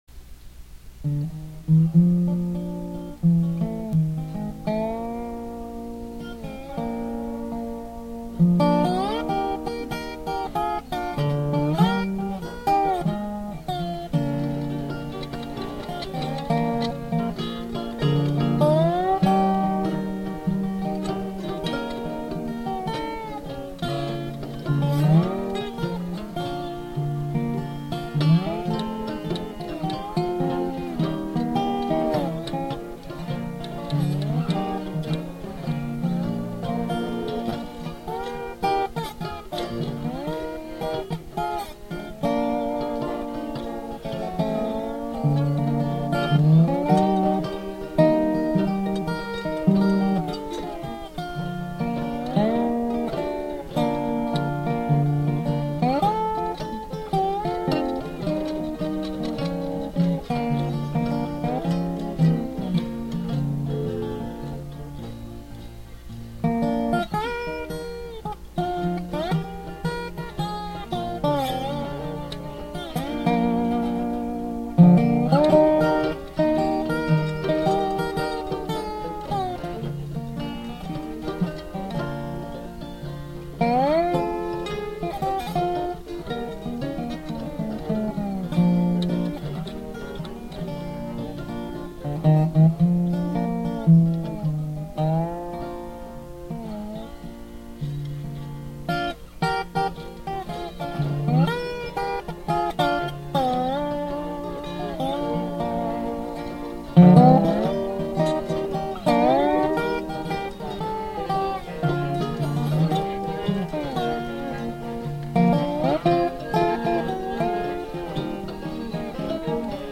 Instrumental with Dobro, Banjo, Guitar, Mandolin and Fiddle
Folk